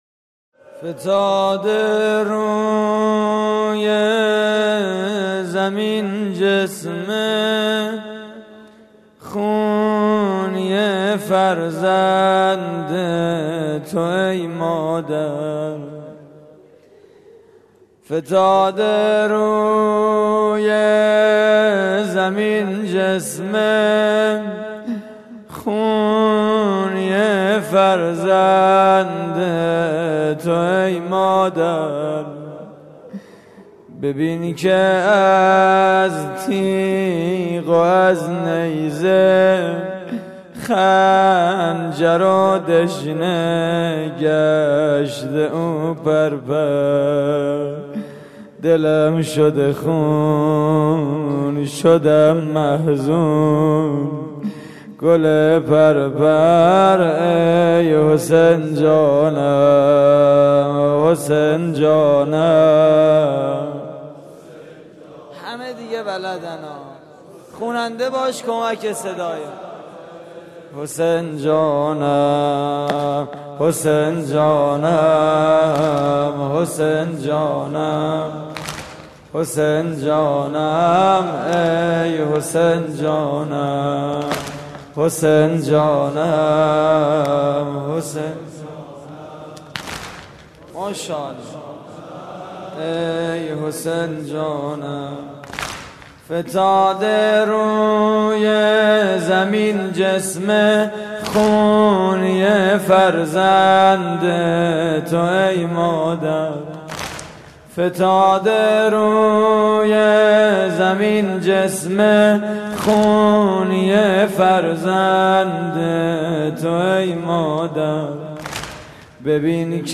واحد: حسین جانم
مراسم عزاداری شب دوازدهم (محرم 1433)